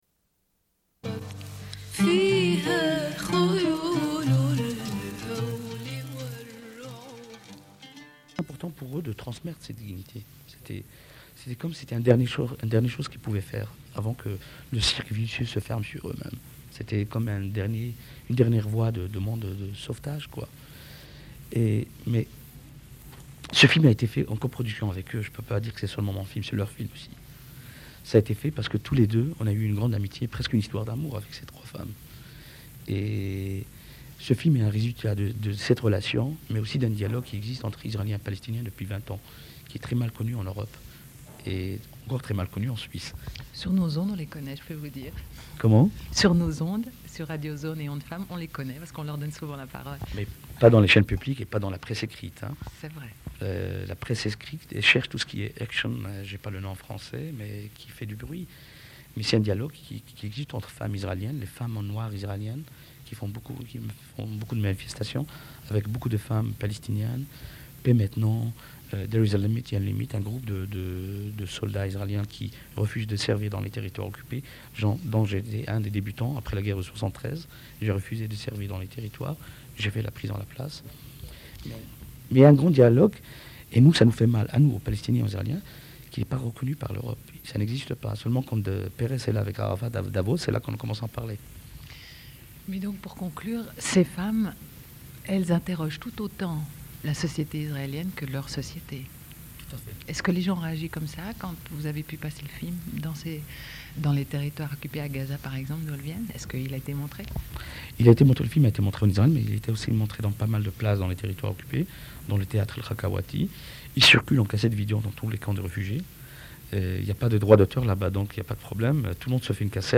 Une cassette audio, face B29:11